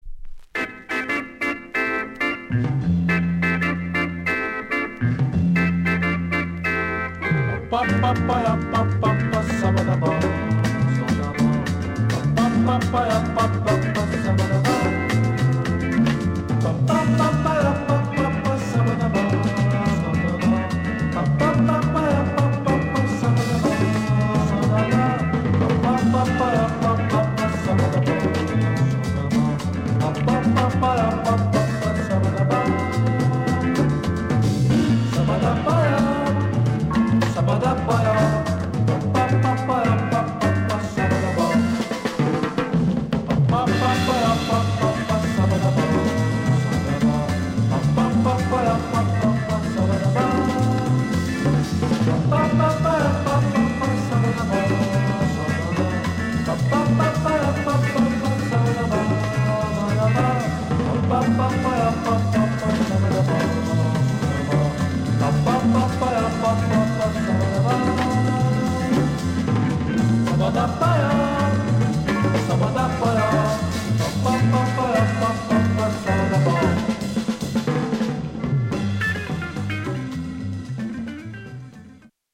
【盤面・コンディション】B/C (B4／B5に薄いチリノイズの箇所あり）
(60年代のブラジル盤はコンディション完璧な物を見つけるのは難しいので曲間等の多少のチリノイズは予めご了承ください。)